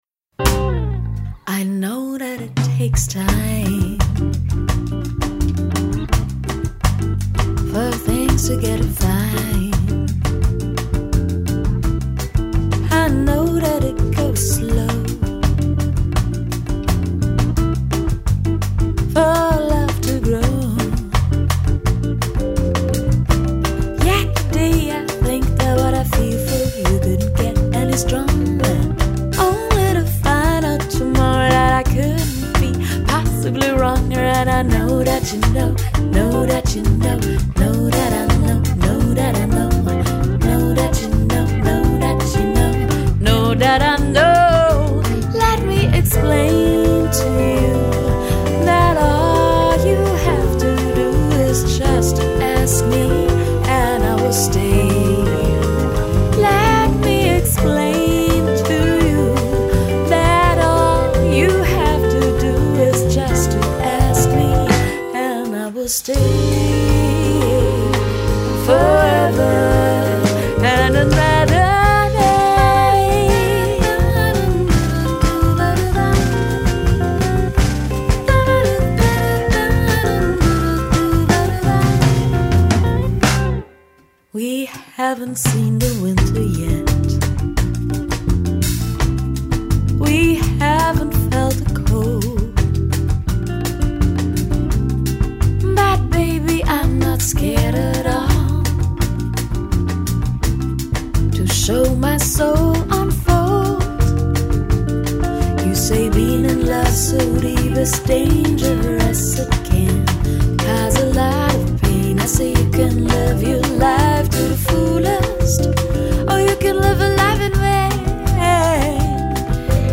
Soul / Jazz / Vocal.
singer-guitarist